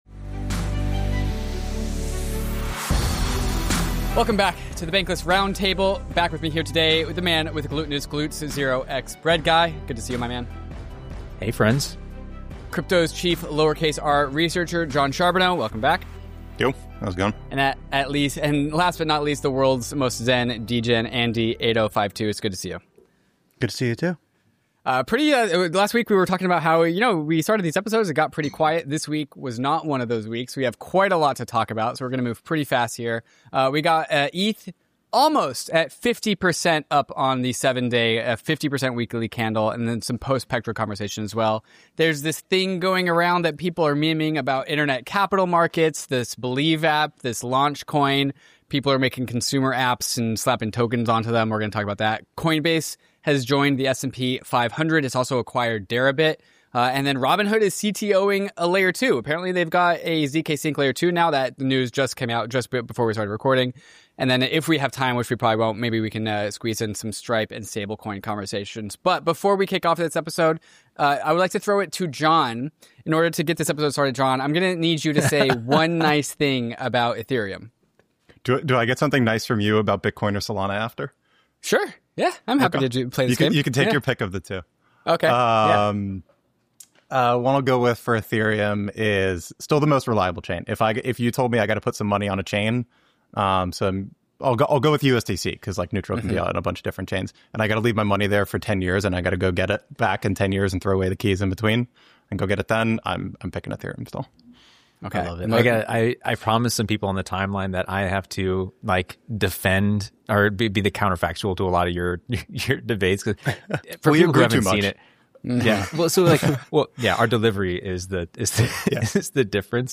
The panel analyzes the impact of the Believe app and Launchcoin on internet capital markets, Coinbase's entry into the S&P 500, and Robinhood's new Layer 2 solution. They explore the implications of these developments for investor confidence and market liquidity, alongside the rising interest in meme-based tokens.
It's a lively exchange of ideas for any crypto enthusiast! 00:00 forum Ask episode play_arrow Play